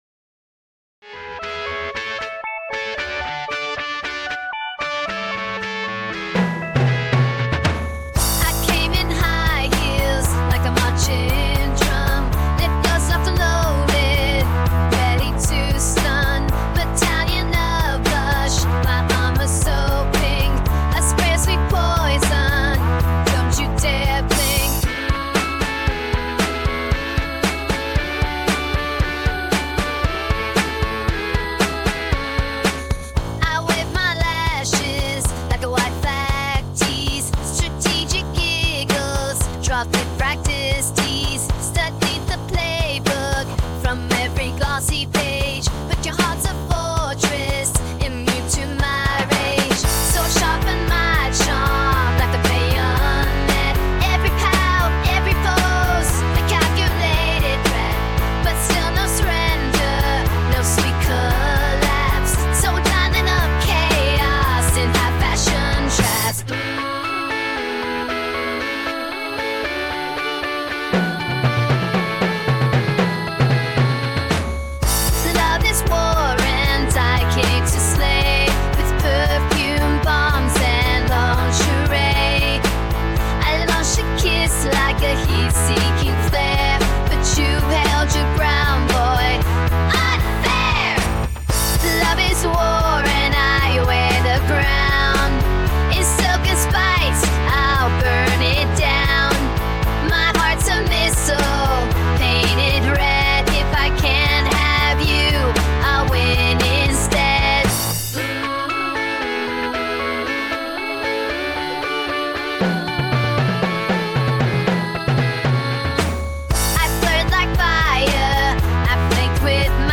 • Genre: Indie Pop/Electro